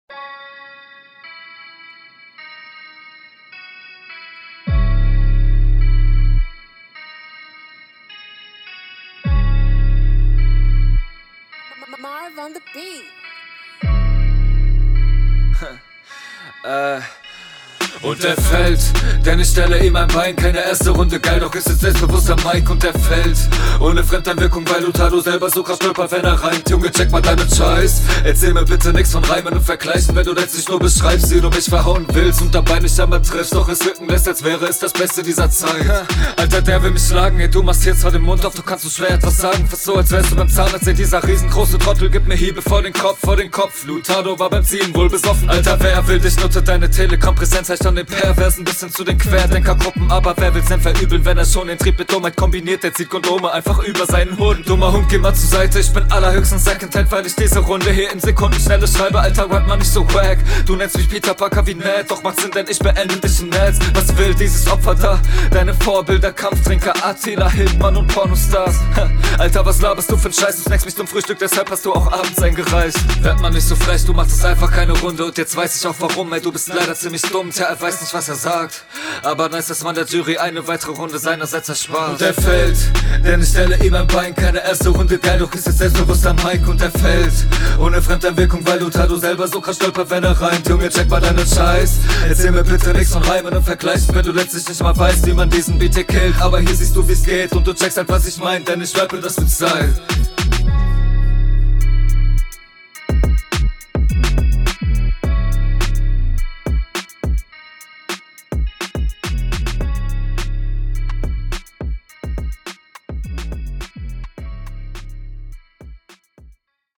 Flow: Ziemlich cool, hat n gylen Drive nach Vorne Text: Vergleich und reim Ding gut …